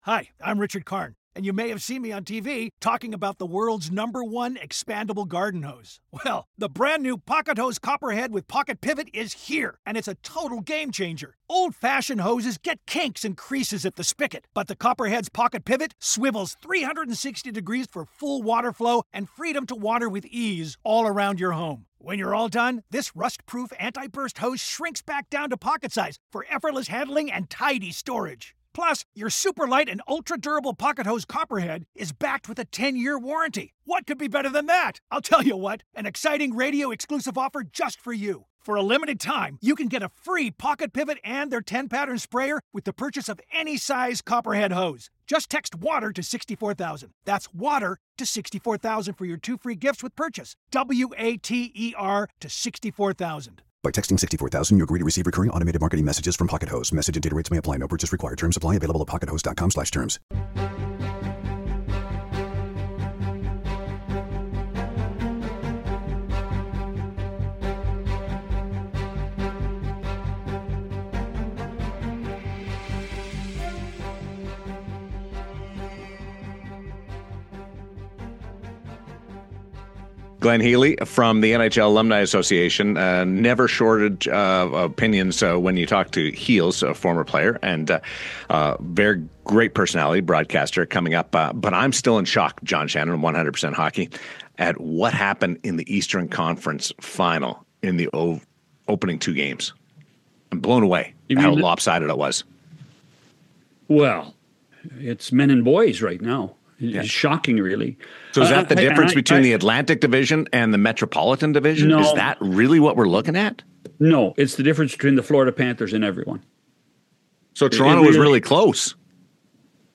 Glenn Healy joins us from his new NHL Alumni Association office in Toronto to share his thoughts on the Maple Leafs' recent playoff exit.